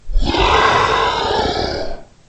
龙的声音 " 龙的咆哮 轻度11
描述：为制作史瑞克而制作的龙声。使用Audacity录制并扭曲了扮演龙的女演员的声音。
Tag: 生物 发声 怪物